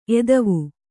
♪ edavu